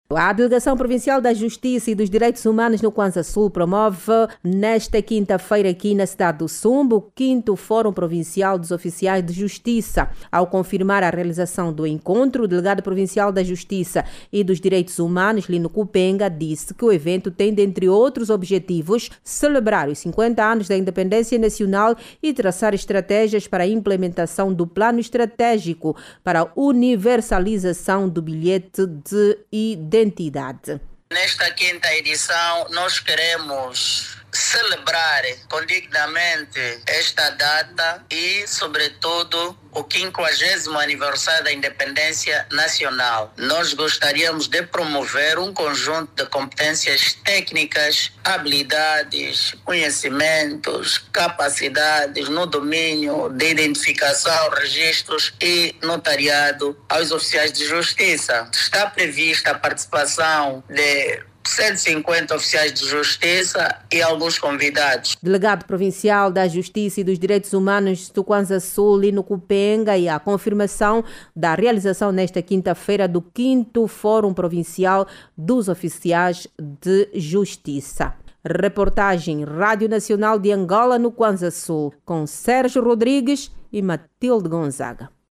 A cidade do Sumbe, na Província do Cuanza-Sul, acolhe a partir de hoje, quinta-feira, 11, o 5º fórum provincial dos oficiais de justiça. O encontro, vai contar com a participação de cento e cinquenta oficiais de justiça e insere-se nas celebrações dos 50 anos de independência nacional. Clique no áudio abaixo e ouça a reportagem